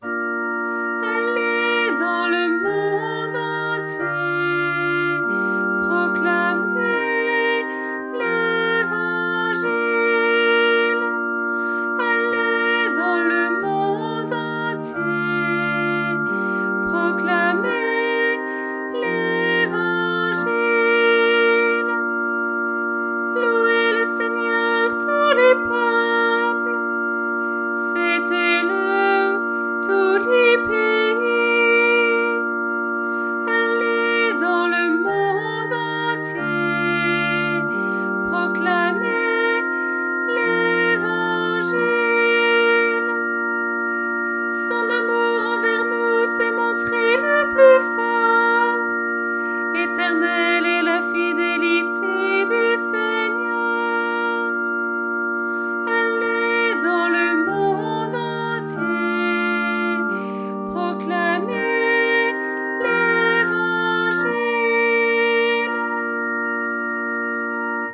Harmonisation SATB - Enregistrement voix soprane